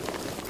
tac_gear_25.ogg